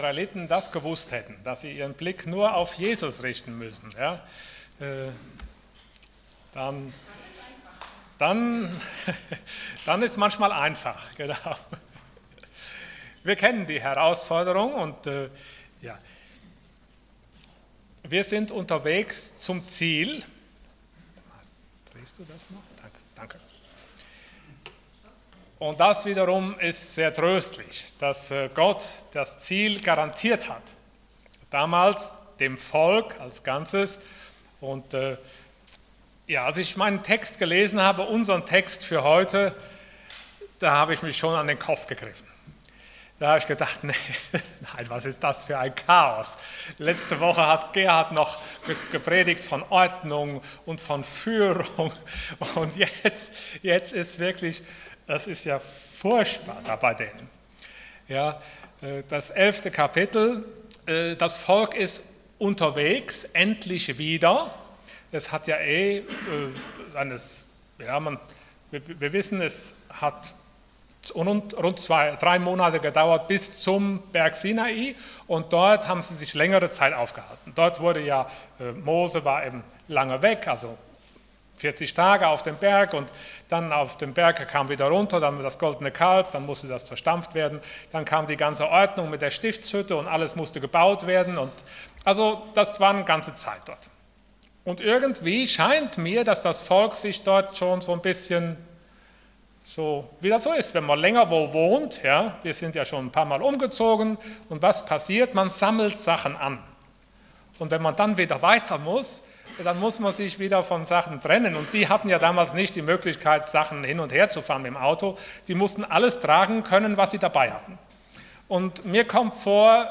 Unterwegs zum Ziel Passage: 4. Mose 11 Dienstart: Sonntag Morgen Wachteln!